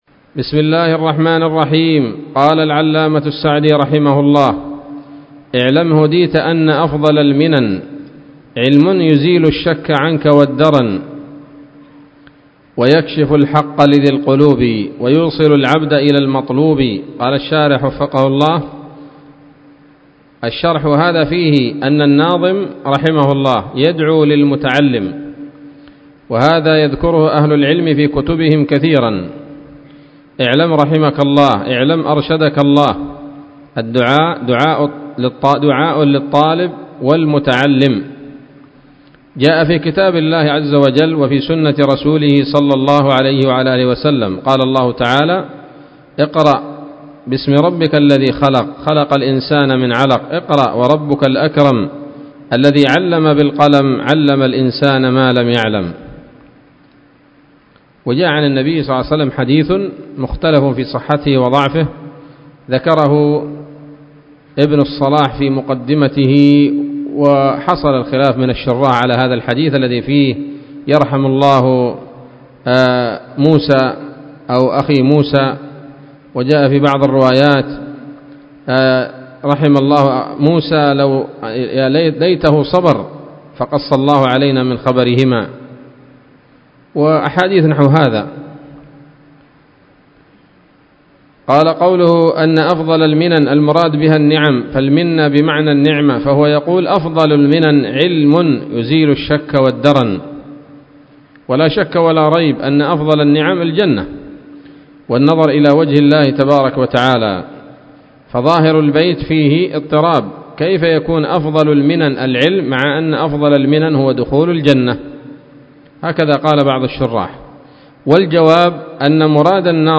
الدرس السابع من الحلل البهية في شرح منظومة القواعد الفقهية